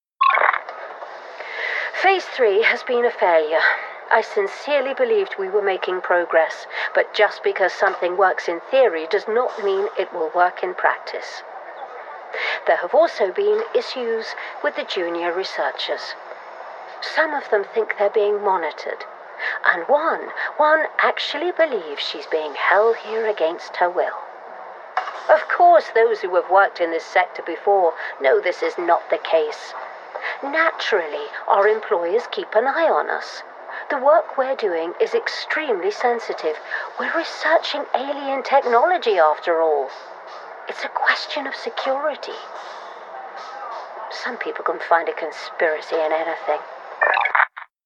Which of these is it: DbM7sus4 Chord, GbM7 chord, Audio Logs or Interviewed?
Audio Logs